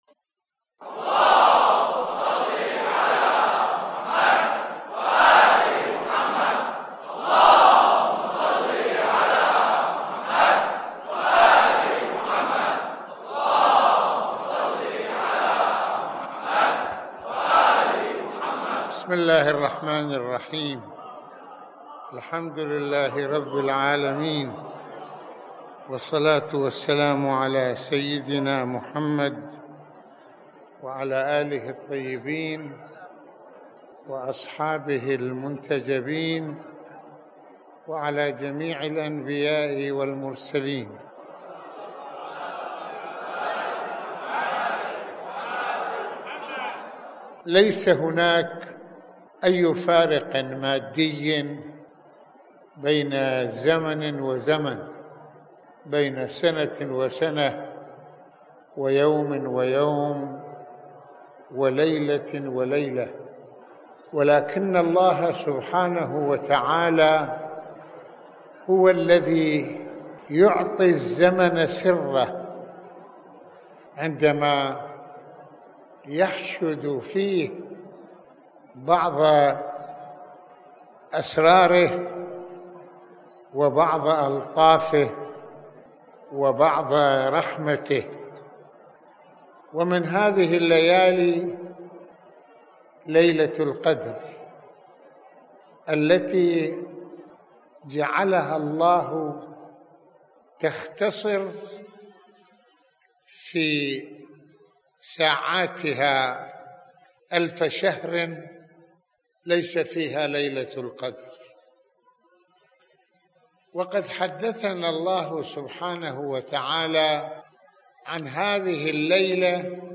يتحدث العلامة المرجع السيد محمّد حسين فضل الله(رض) في هذه المحاضرة عن سورة القدر وأجوائها الروحية وأبعادها الدينية المتعددة الوجوه، من ذلك تنزل الملائكة وحركتهم فيها، ويشير سماحته(رض) إلى ضرورة الإفادة من هذه المحطة الزمنية الهامّة..